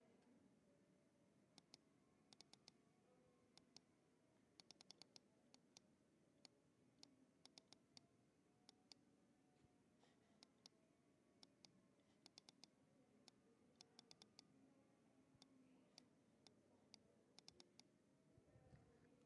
Boom Folie Clicking
描述：单击鼠标计算机。
Tag: 鼠标 电脑 点击